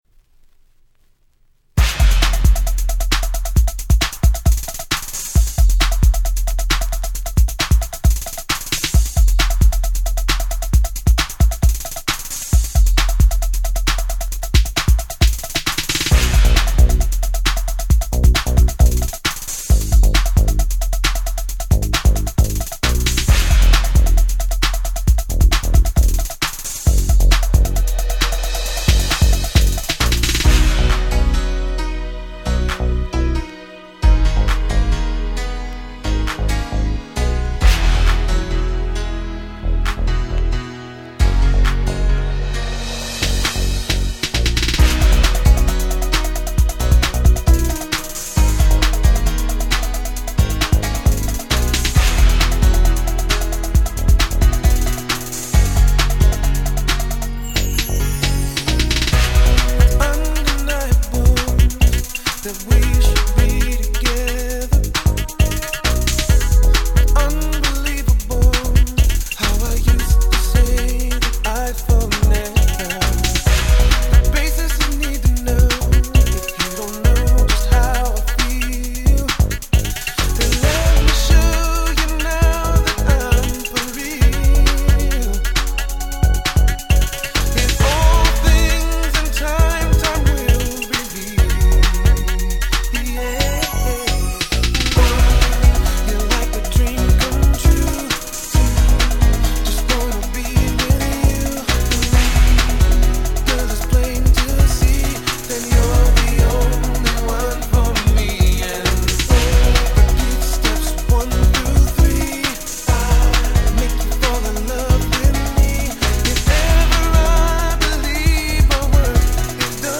99' Super Hit Slow Jam !!
Miami Bass調のフロア向けなRemixも悪くないですが、やっぱりオリジナルですよね〜！